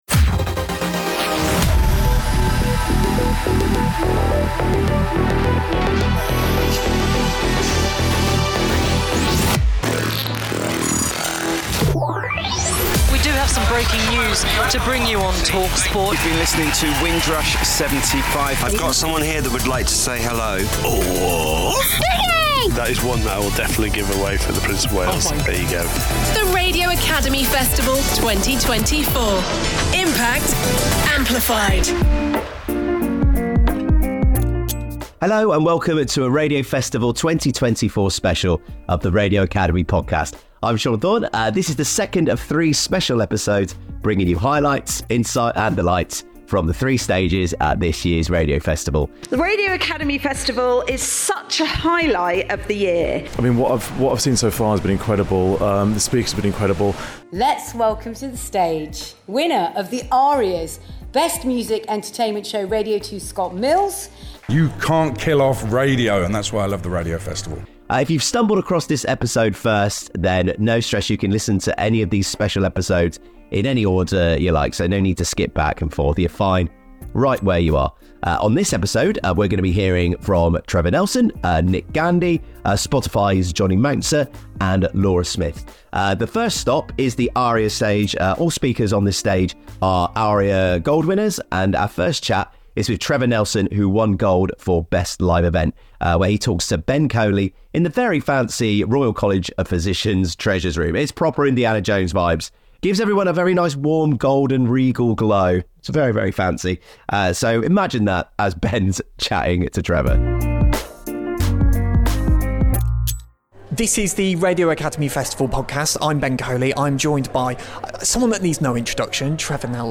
another special Radio Academy podcast from the 2024 Radio Academy Festival.
conversations with some of the speakers